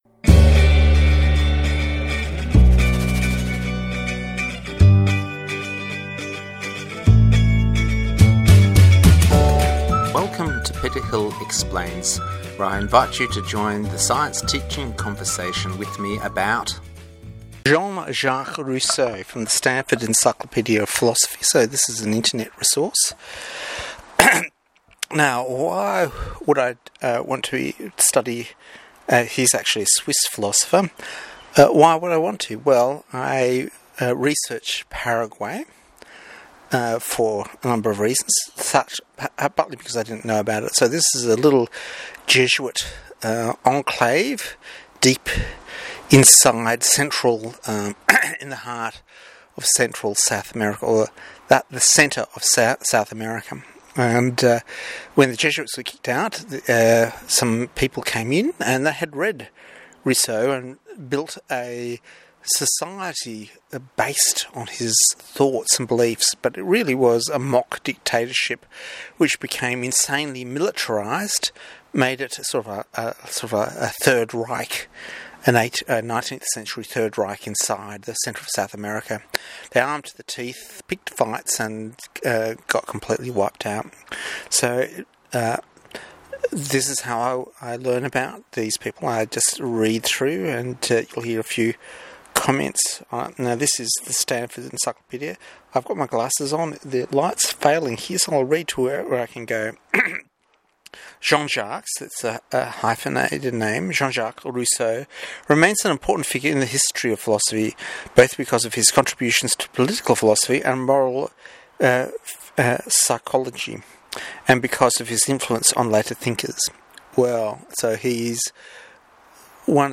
Jean Jacques Rousseau as read from the Standford Encyclopeadia of Philosophy.